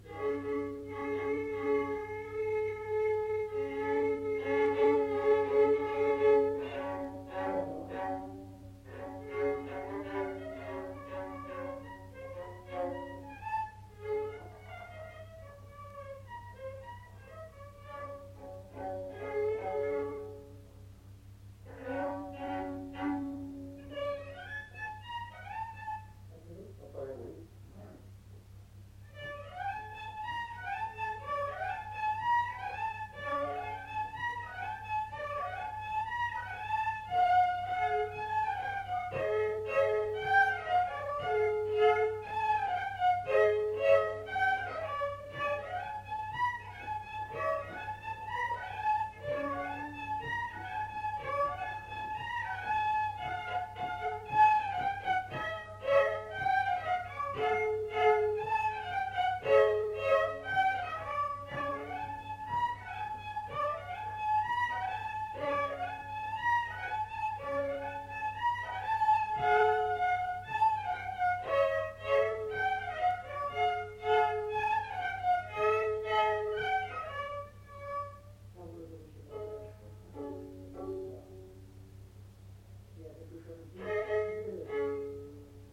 Valse
Aire culturelle : Lomagne
Département : Gers
Genre : morceau instrumental
Instrument de musique : violon
Danse : valse
Notes consultables : Deux violons.